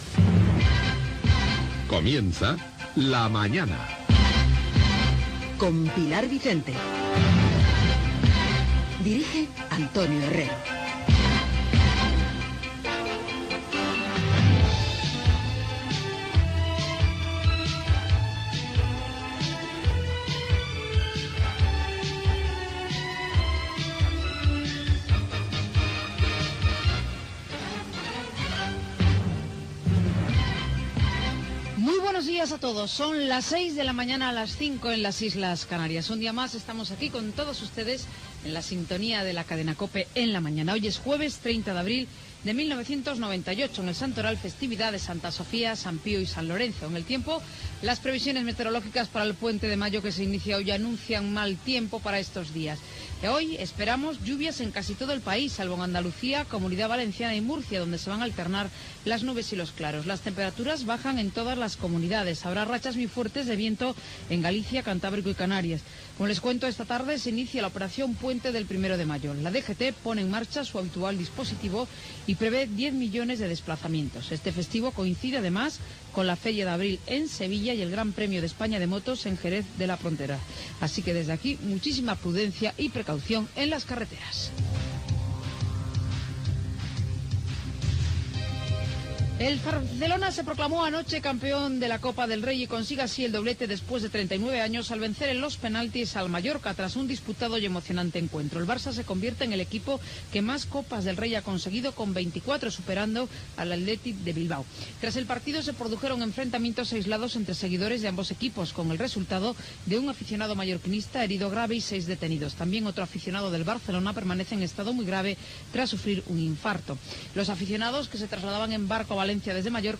Careta del programa, inici de la primera hora del programa, data, santoral, el temps, el trànsit, repàs a l'actualitat (El F.C:Barcelona guanya la Copa del Rei, el parc de Doñana, ), comentari de Federico Jiménez Losantos
Info-entreteniment